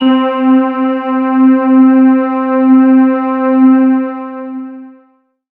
37l01pad1-c.wav